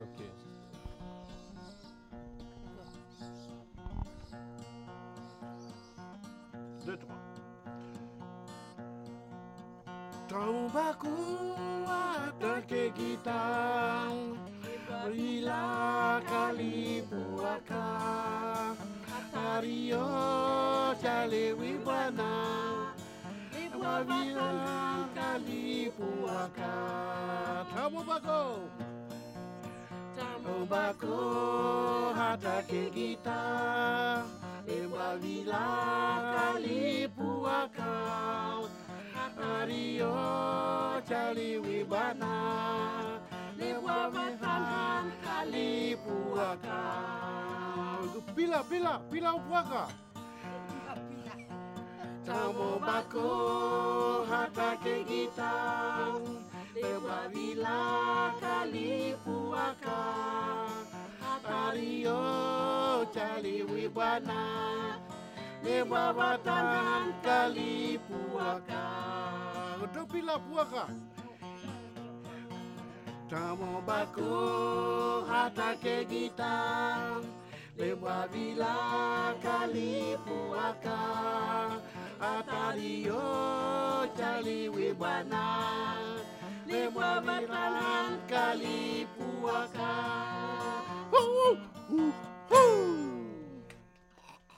Ein Vamale Song